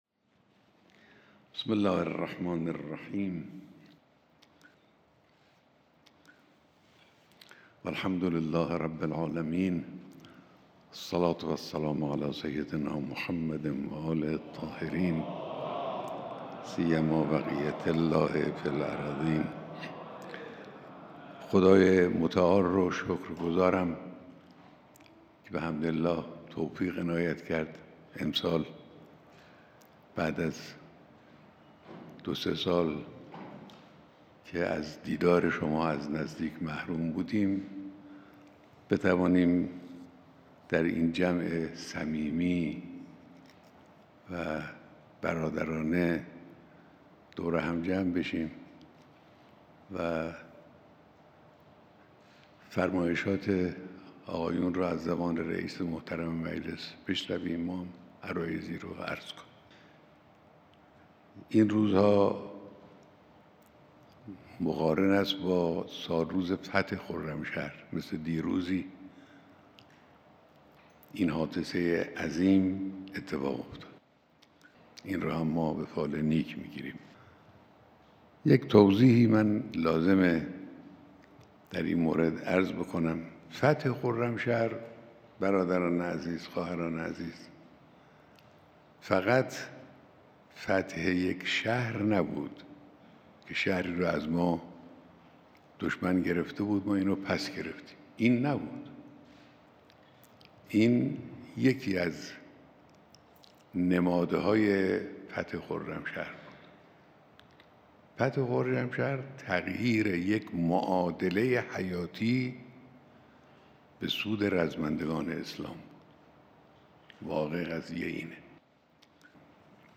بیانات در دیدار با نمایندگان مجلس شورای اسلامی